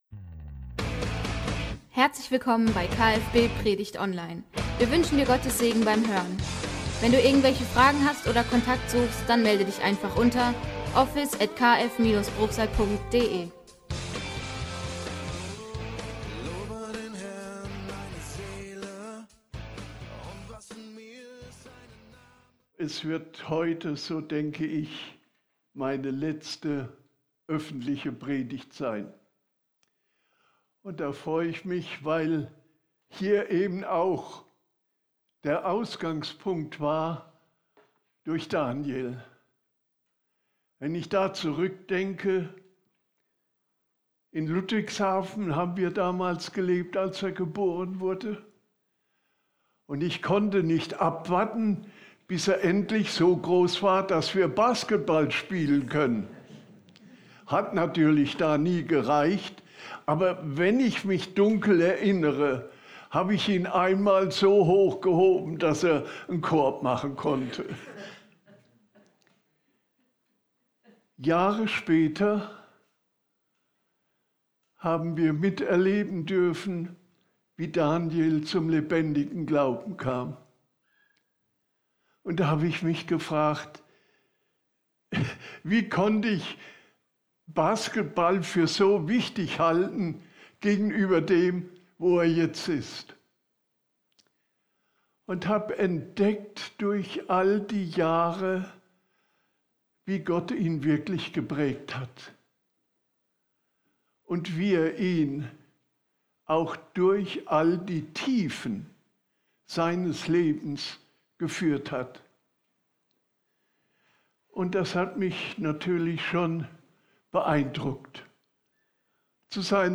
Wie Gemeinde lebt ~ Predigtpodcast - Kirche für Bruchsal Podcast